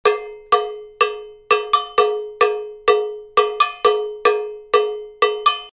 LP Rock Classic Ridge Rider Cowbell
Full Description Watch/Listen 1 Customer Reviews LP Rock Classic Ridge Rider Cowbell - Product Information This uniquely innovative patented bell starts with the Sergio Salsa Cowbell and features a yellow Jenigor plastic bar across the top edge which is secured with specially designed rivets. The Jenigor bar has a dampening effect on the sound which eliminates the need to attach unsightly duct tape to remove unwanted overtones.
It is similar to the Rock Ridge Rider but with a deeper sound.